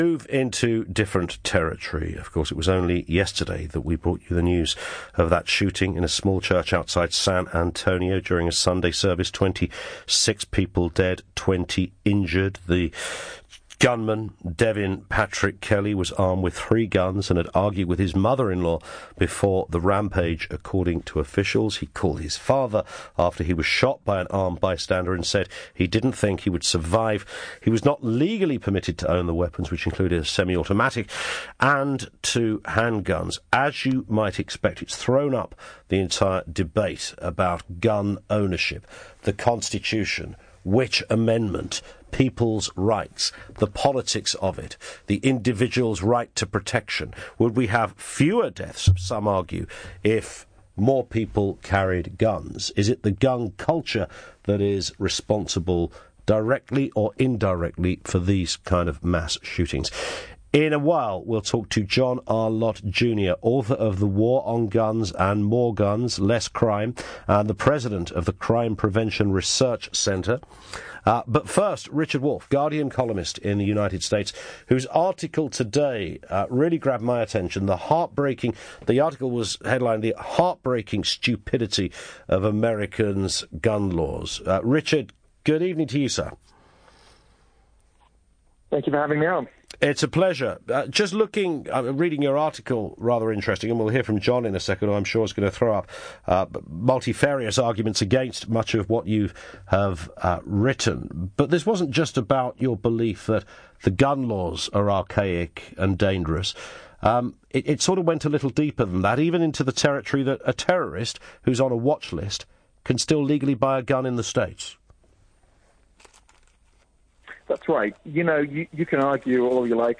Debate on Britain's LBC radio show about the US's gun control regulations - Crime Prevention Research Center